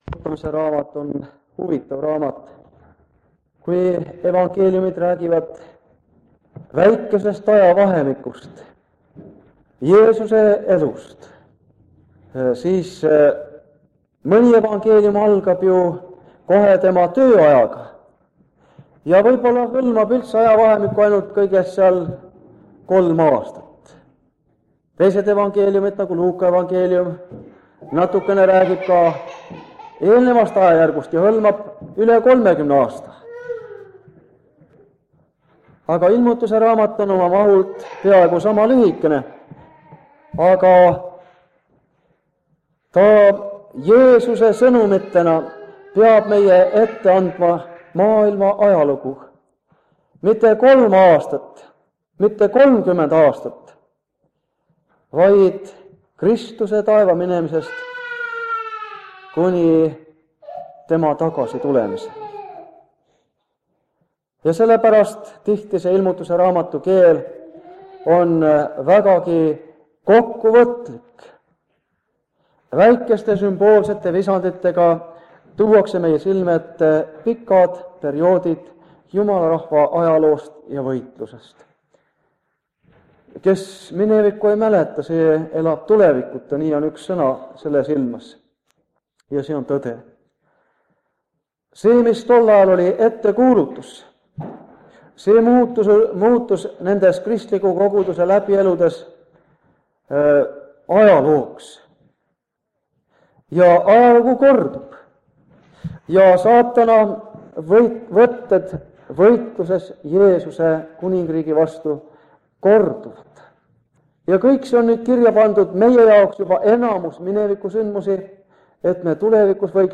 Ilmutuse raamatu seeriakoosolekud Kingissepa linna adventkoguduses